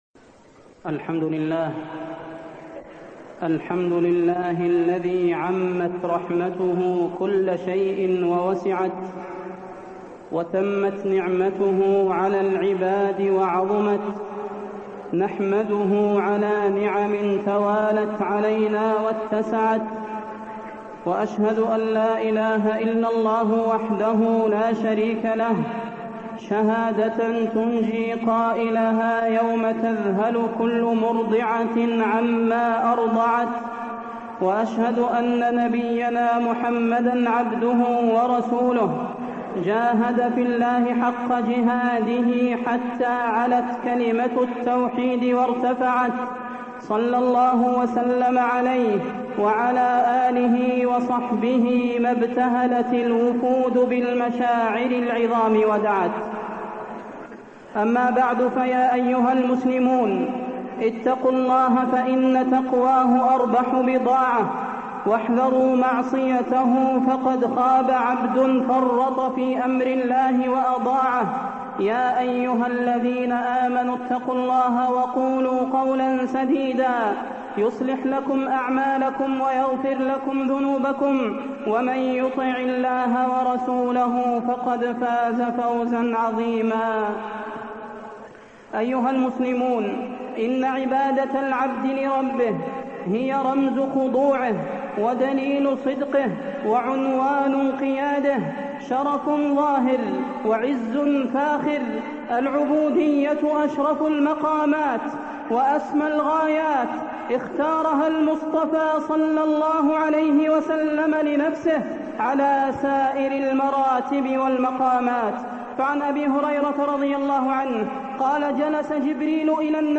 خطبة ثمرات الحج وفيها: عظم نعمة الحج، وجوب شكر نعمة الحج، وأمارات الحج المبرور، وكل مناسك الحج تذكر بالله
تاريخ النشر ١٧ ذو الحجة ١٤٢٢ المكان: المسجد النبوي الشيخ: فضيلة الشيخ د. صلاح بن محمد البدير فضيلة الشيخ د. صلاح بن محمد البدير ثمرات الحج